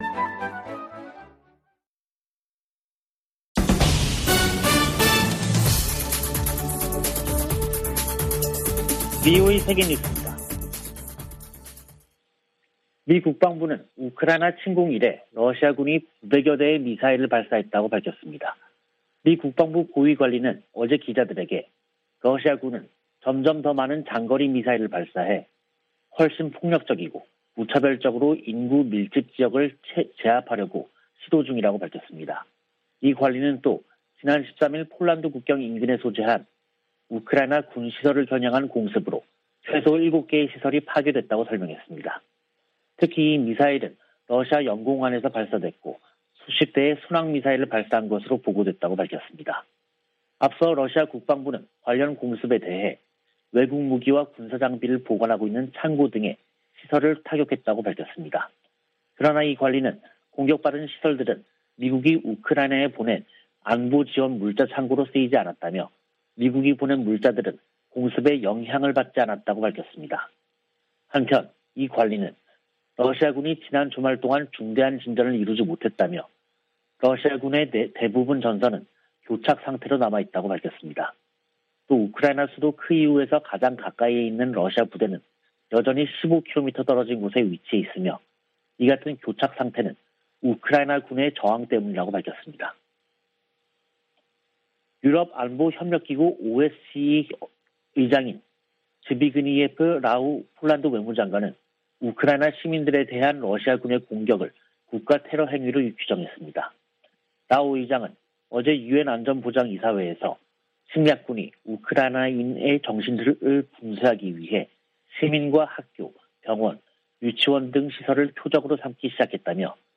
VOA 한국어 간판 뉴스 프로그램 '뉴스 투데이', 2022년 3월 15일 2부 방송입니다. 북한이 이동식발사대(TEL)에서 미사일을 쏠 때 사용하는 콘크리트 토대를 순안공항에 증설한 정황이 포착됐습니다. 백악관은 북한의 신형 ICBM 발사가 임박했다는 보도와 관련해, 예단하지 않겠다고 밝혔습니다. 백악관 국가안보보좌관이 중국 고위 당국자와 만났습니다.